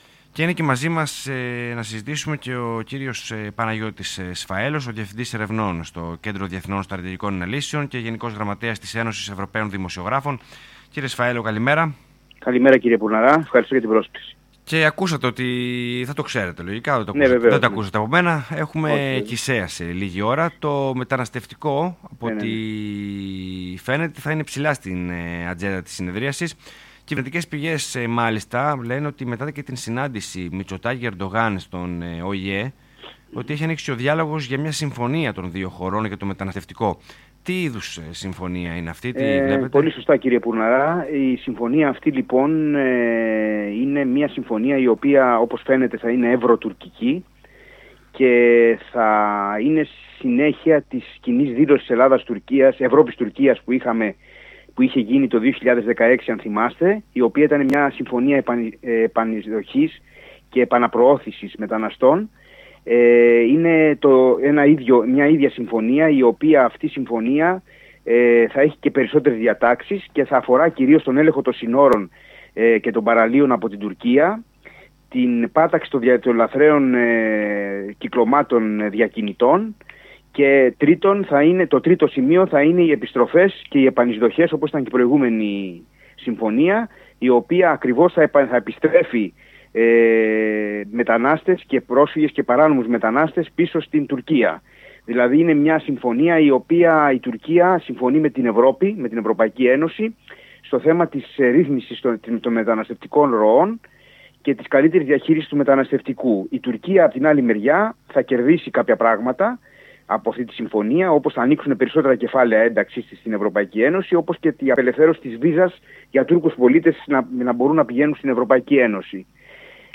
ΣΥΝΕΝΤΕΥΞΗ ΣΤΟΝ ΕΛΛΑΔΑ FM 94,3